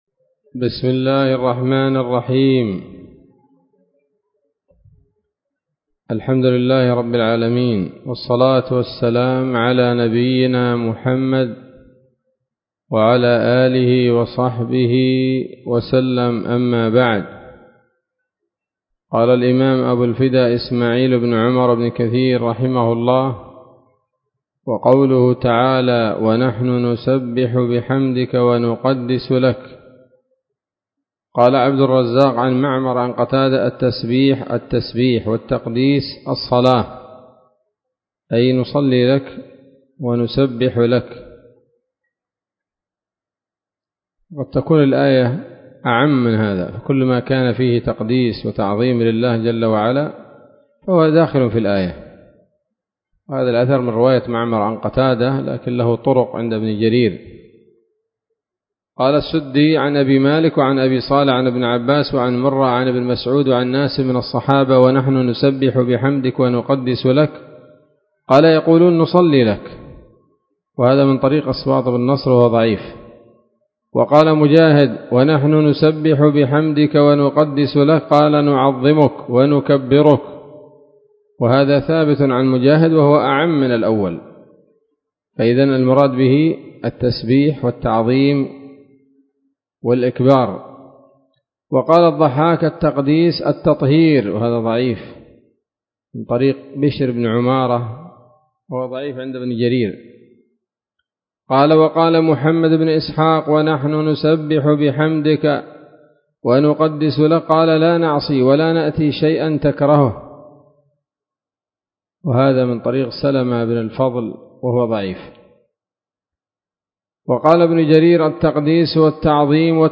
الدرس الخامس والثلاثون من سورة البقرة من تفسير ابن كثير رحمه الله تعالى